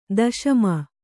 ♪ daśama